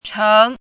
怎么读
chéng
cheng2.mp3